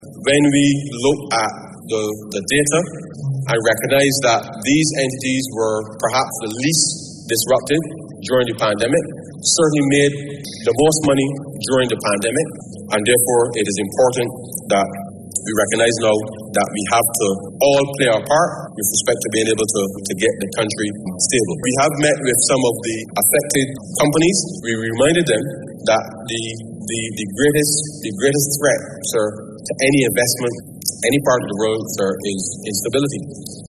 He explained why the telecoms, insurance and finance sectors were targeted.